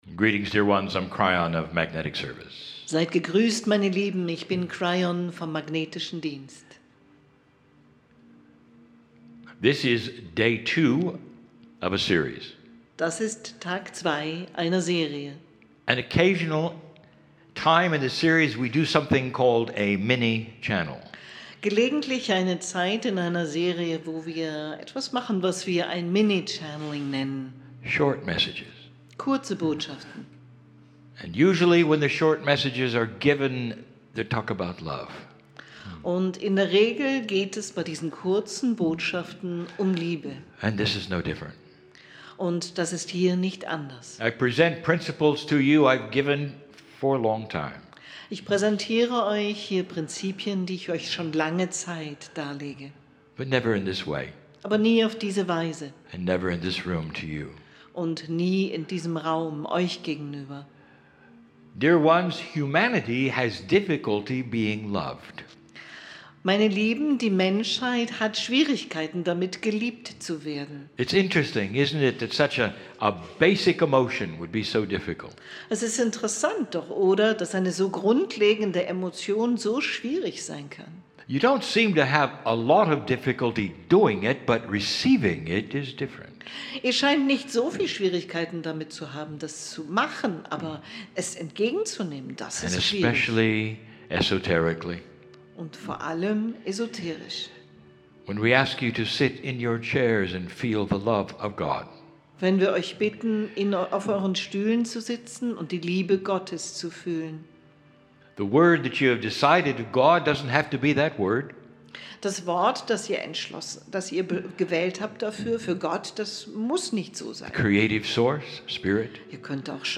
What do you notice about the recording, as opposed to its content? SOUTHERN GERMANY FIRST DISCOVERY WORKSHOP IN GERMANY